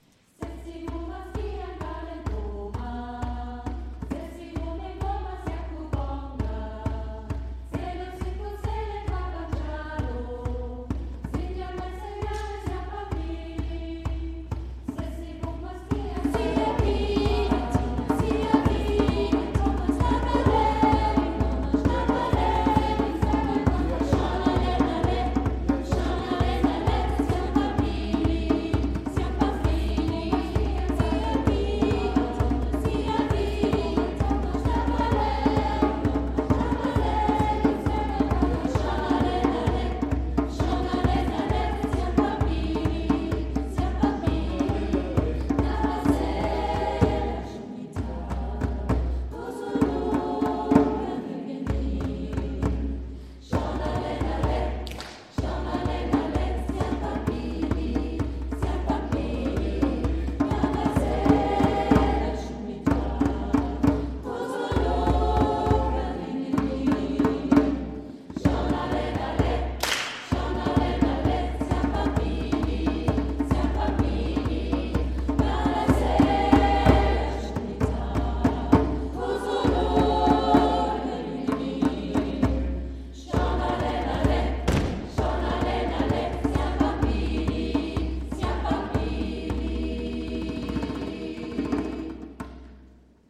Unser Chor begleitete diese Messe mit neuen afrikanischen Gesängen die den einen oder anderen Kirchenbesucher zum "mitshaken" animierten.
Die afrikanischen Lieder aus dem Gottesdienst